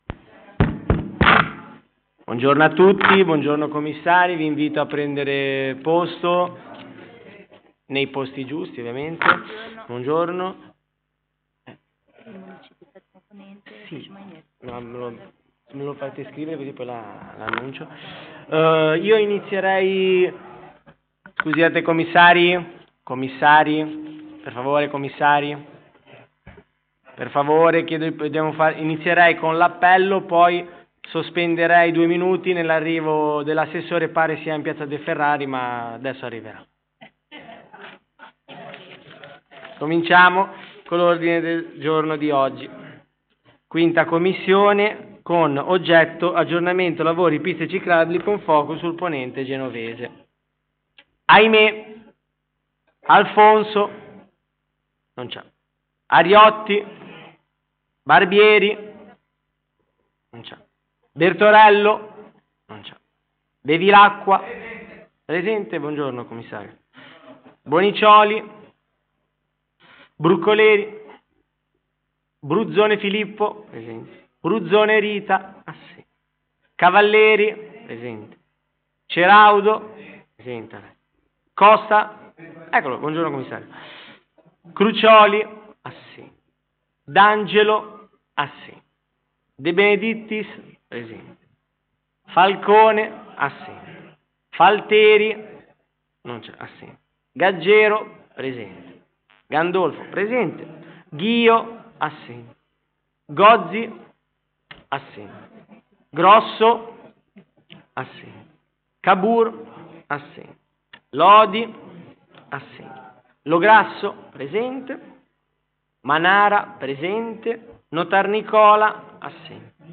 Luogo: Presso la Sala Consiliare di Palazzo Tursi - Albini
Audio seduta